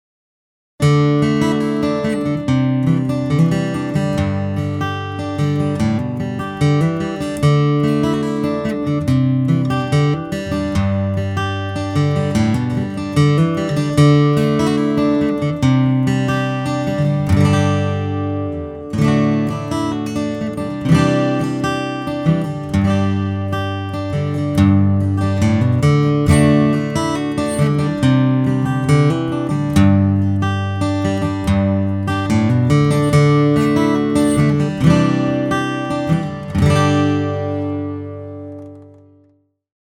Here's a recording with an AT4050 pointed at the body of the guitar, below the bridge, about 9 inches away...with a regular dynamic mic pointed at the 12th fret about 6 inches away.
Attachments stereogit.mp3 stereogit.mp3 933.7 KB · Views: 171